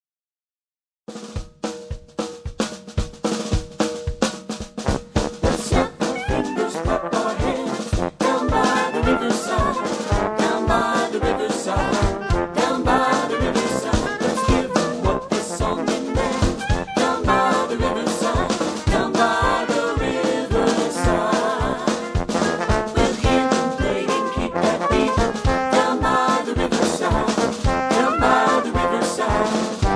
karaoke, backing tracks
gospel